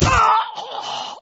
scream9.ogg